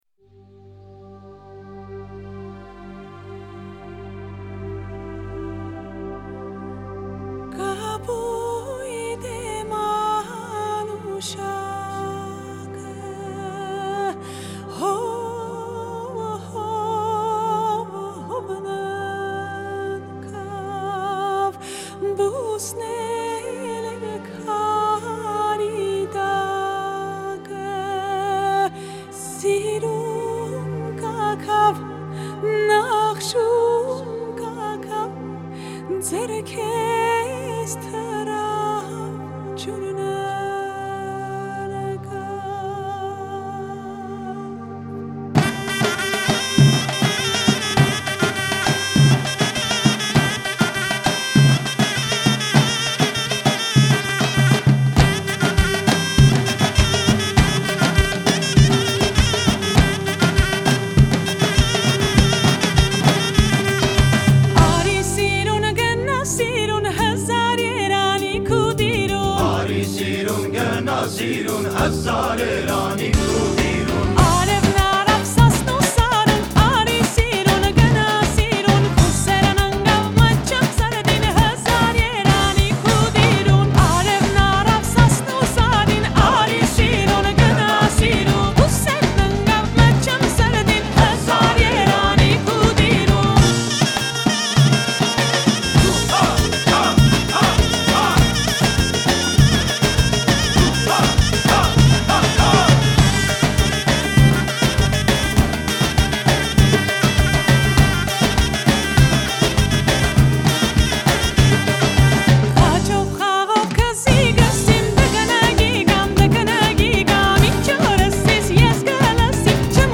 Трек размещён в разделе Русские песни / Армянские песни.